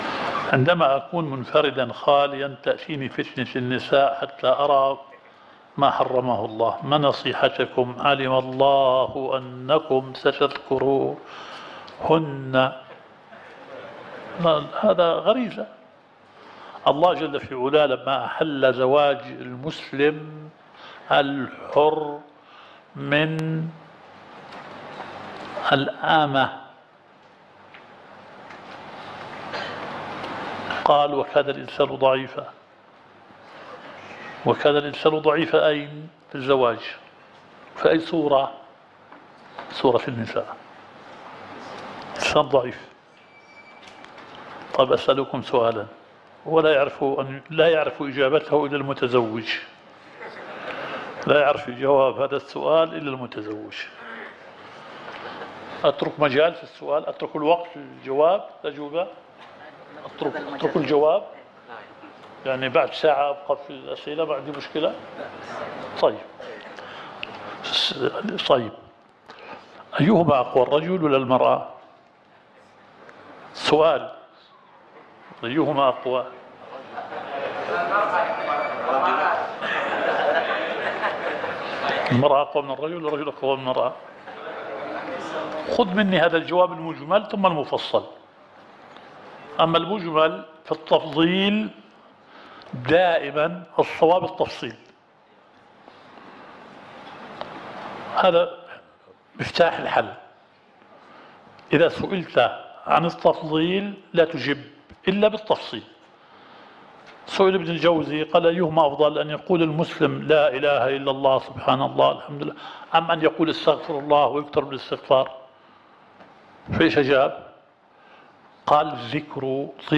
الدورة الشرعية الثالثة للدعاة في اندونيسيا – منهج السلف في التعامل مع الفتن – المحاضرة الثانية.